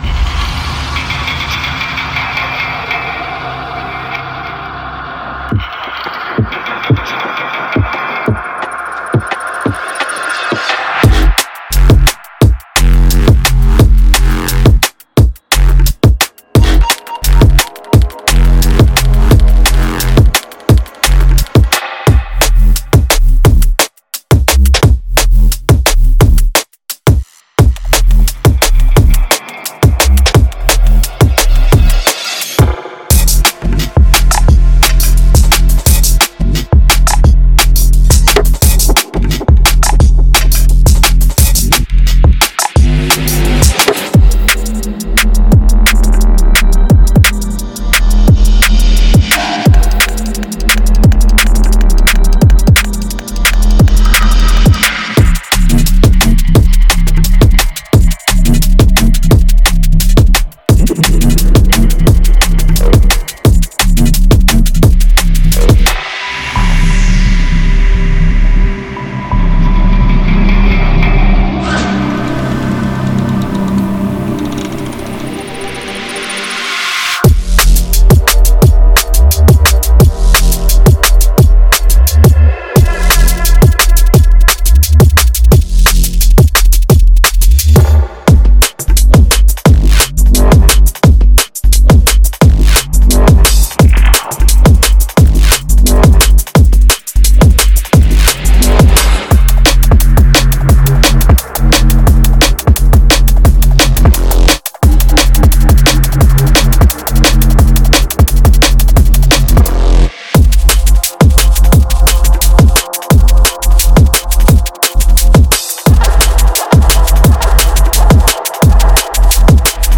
Genre:Drum and Bass
デモサウンドはコチラ↓